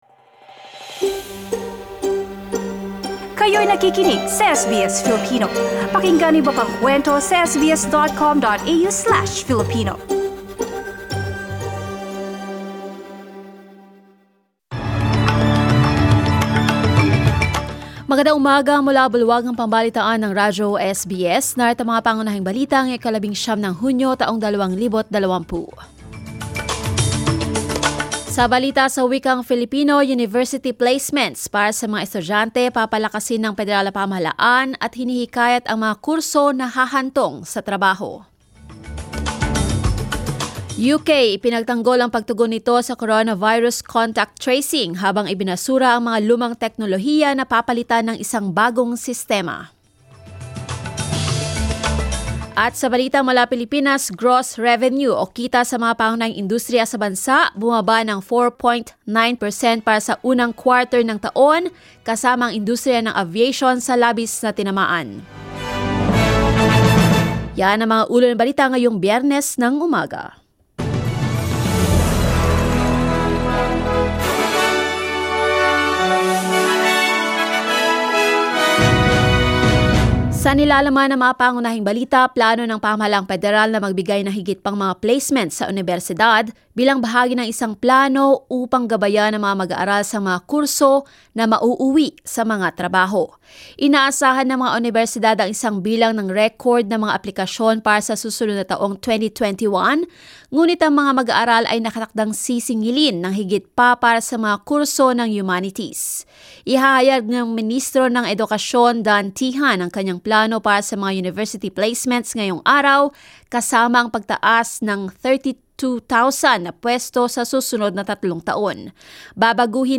SBS News in Filipino, Monday 19 June